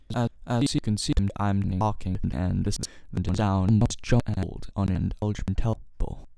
Recordings on playback choppy, skipping
I’m trying to record audio on Audacity for various reasons with my own mics (I currently use a Blue Spark attached to a Focusrite Scarlett 2i2 USB with an XLR cable, with the Scarlett 2i2 connected by USB to my Mac). Whenever I record my voice and hear the playback, there’s a choppy effect to it. Arguably the quality of the recording is fine; however, the resulting playback is just unintelligible and garbled.
In the case of “garbled.mp3” there are bits missing, but also some parts are repeated.